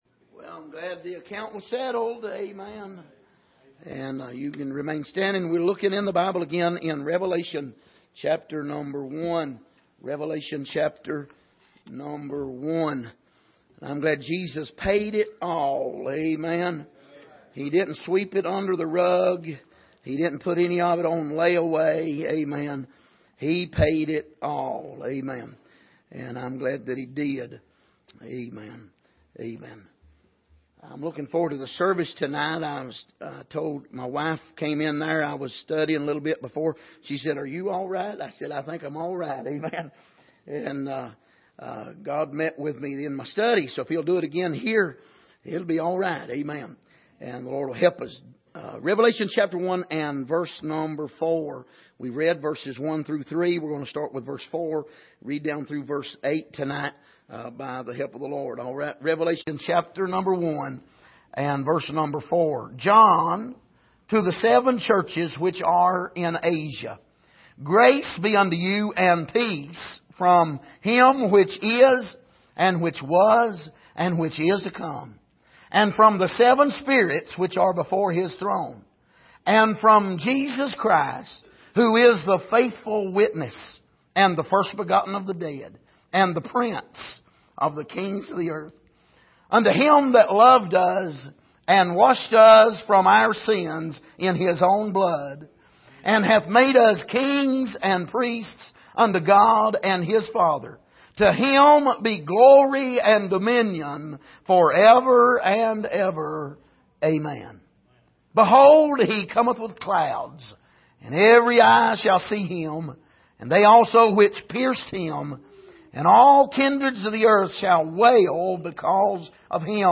Passage: Revelation 1:4-8 Service: Sunday Evening